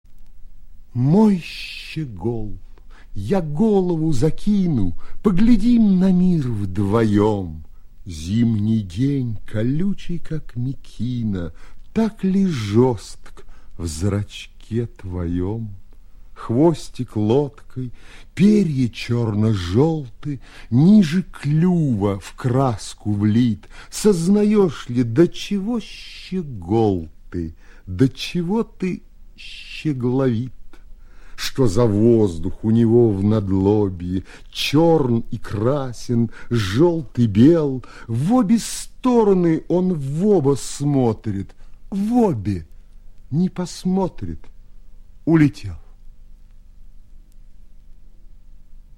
1. «Осип Мандельштам читает Михаил Козаков – Мой щегол, я голову закину (1936)» /